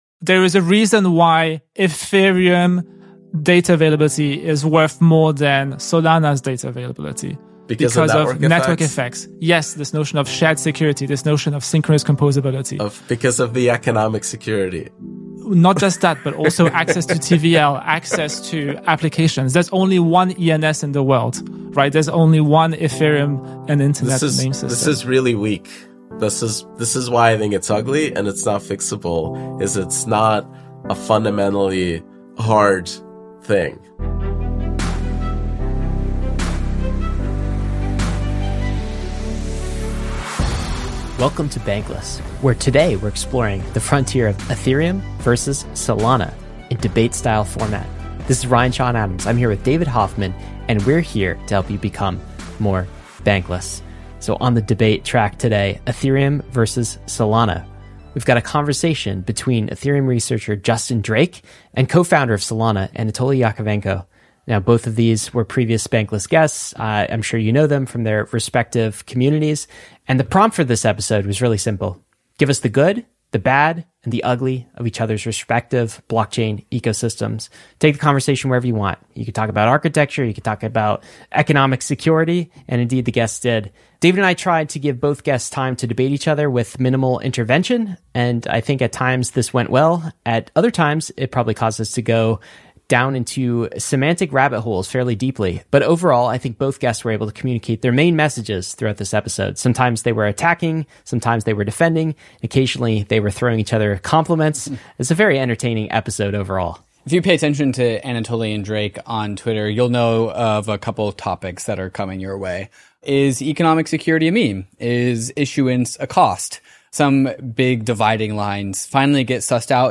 ------ Ethereum vs. Solana. That’s the debate we’re having today.